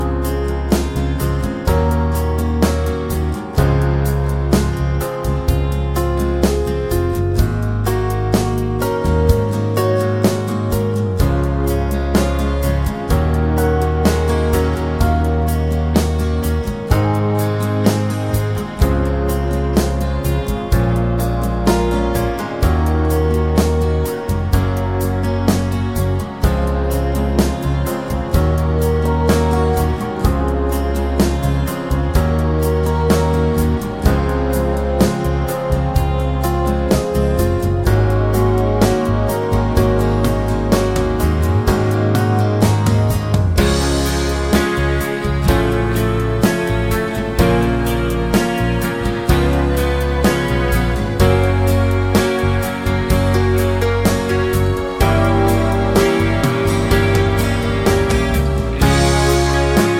no Backing Vocals Country (Male) 4:29 Buy £1.50